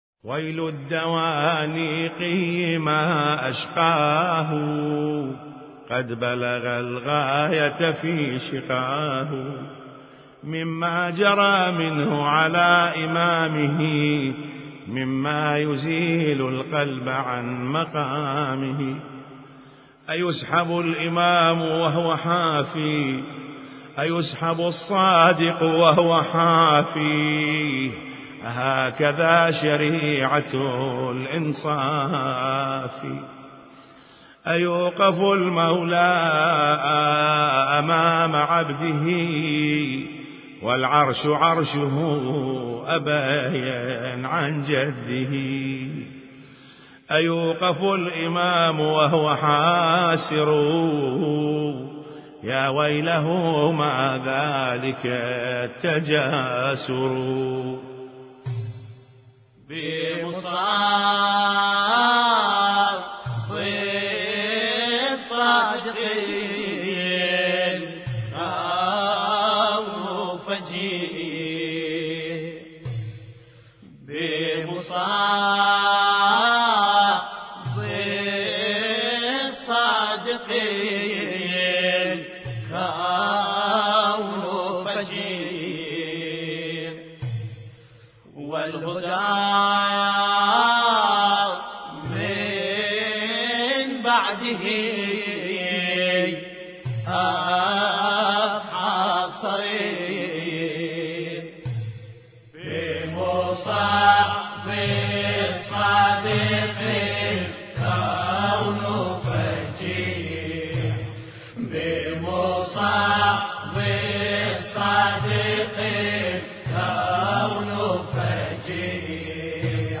مراثي الامام الصادق (ع)